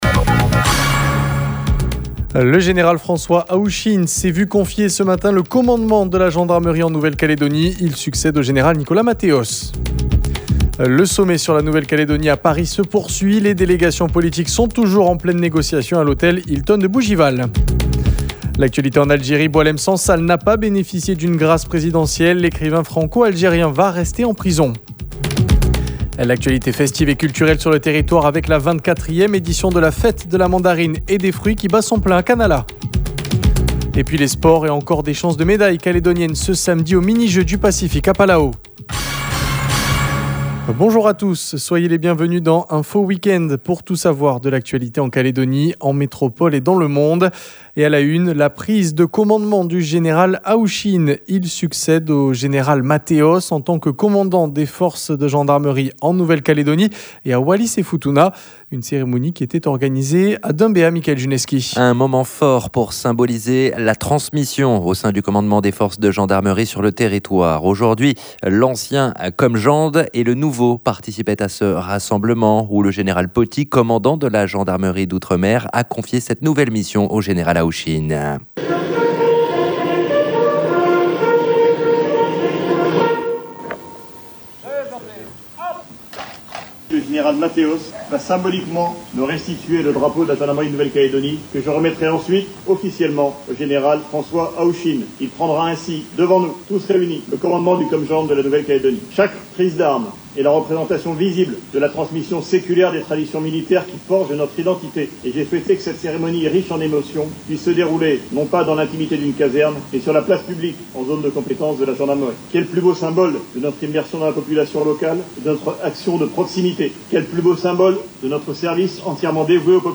JOURNAL : WEEK-END SAMEDI MIDI 05/07/25
Reportage à suivre tout à l’heure, dans notre journal de midi.